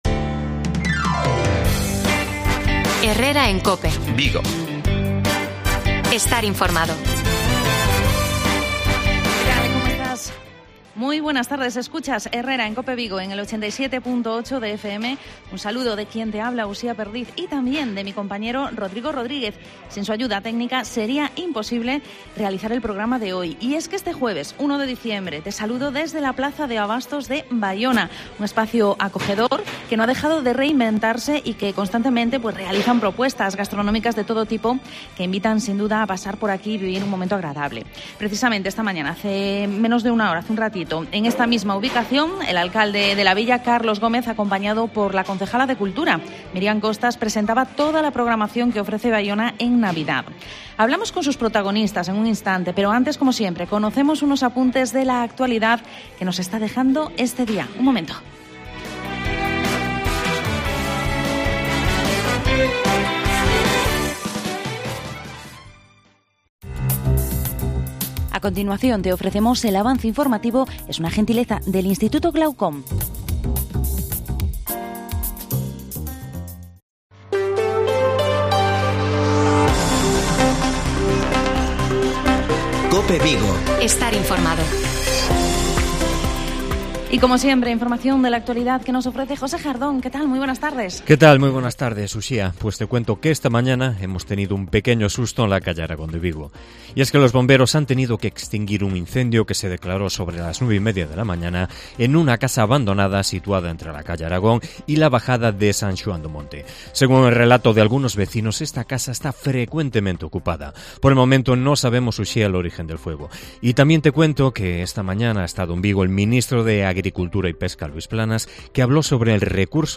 AUDIO: ESPECIAL HERRERA COPE VIGO DESDE BAIONA ENCENDIDO NAVIDEÑO 2022